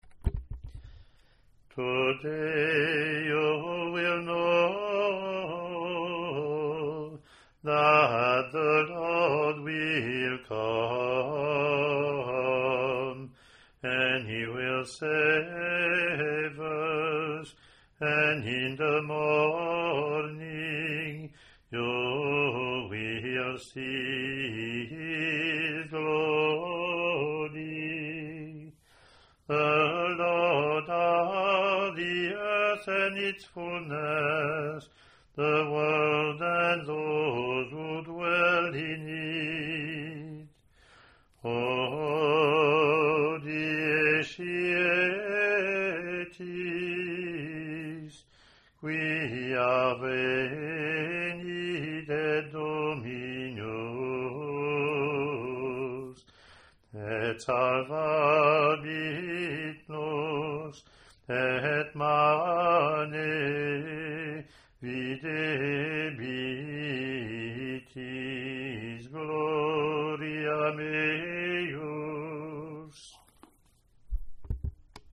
English antiphon – English verse – Latin antiphon and verse)